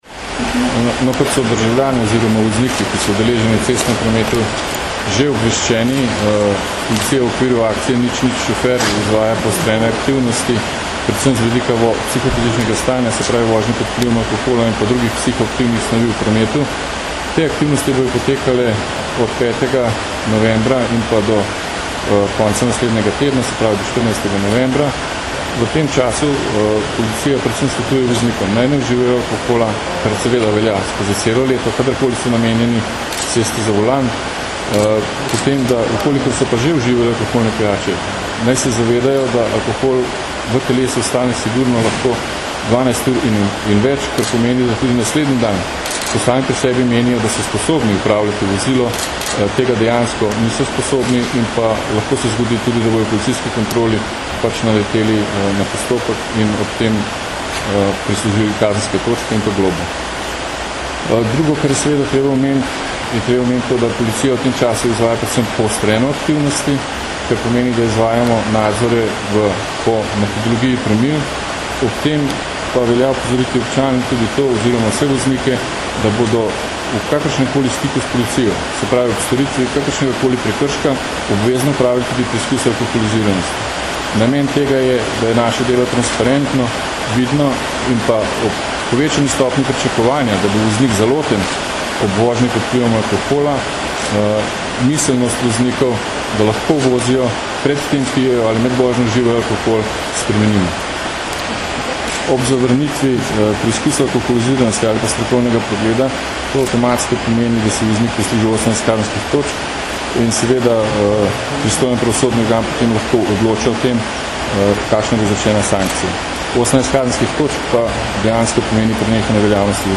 Zvočni posnetek izjave